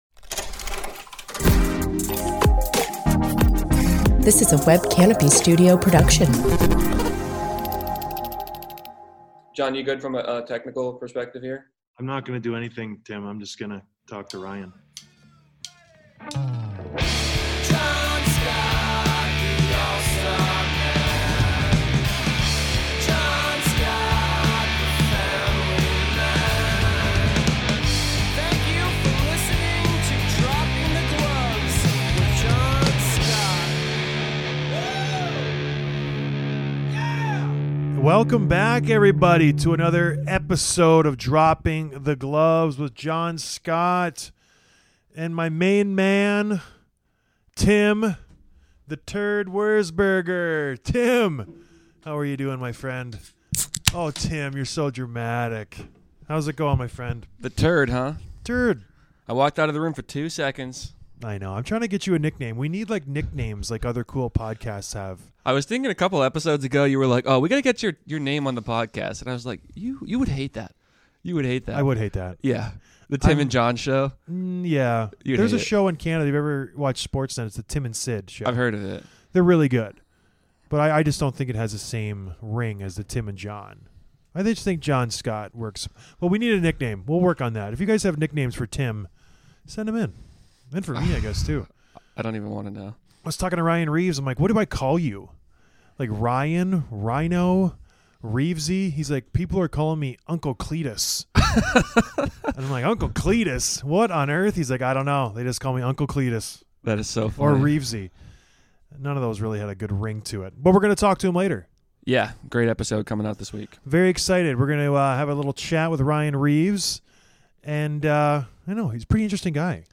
Interview with Ryan Reaves, VGK Enforcer